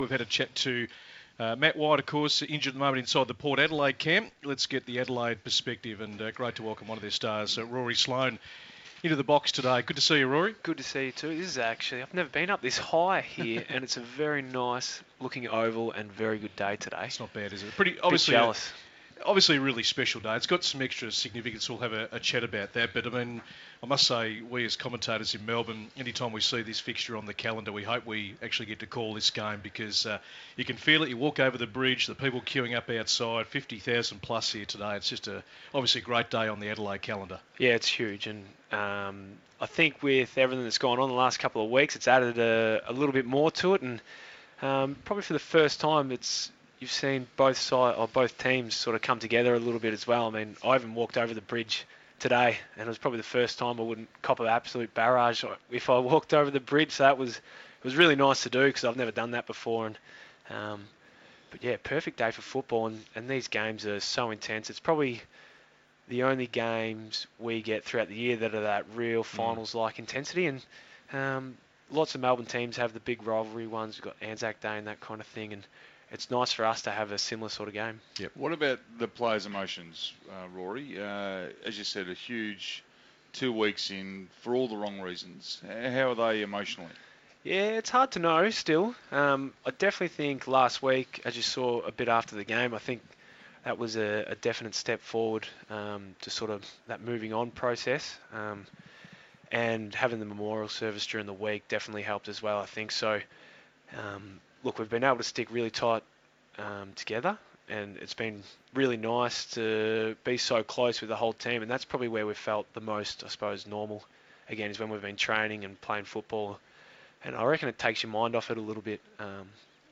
Injured midfielder Rory Sloane joined the SEN commentary team on Showdown day ahead of the first bounce.